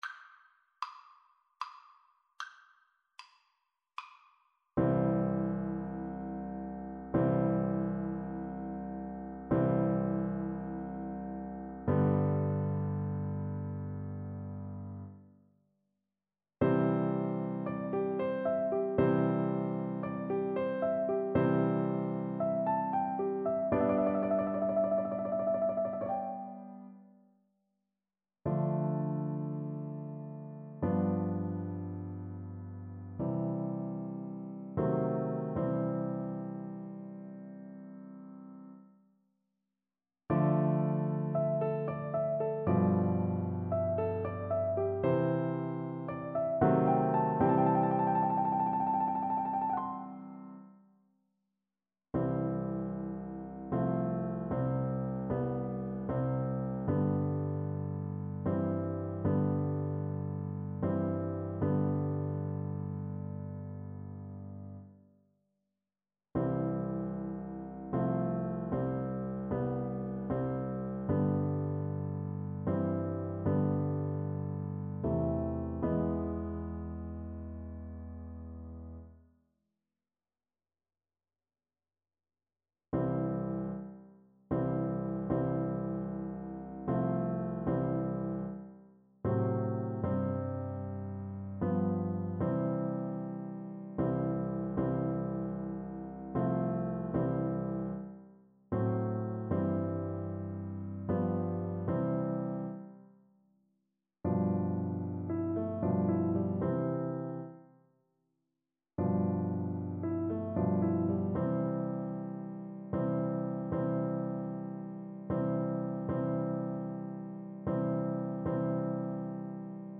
Play (or use space bar on your keyboard) Pause Music Playalong - Piano Accompaniment Playalong Band Accompaniment not yet available transpose reset tempo print settings full screen
Flute
Andante =76
C major (Sounding Pitch) (View more C major Music for Flute )
3/8 (View more 3/8 Music)
E5-C7
Classical (View more Classical Flute Music)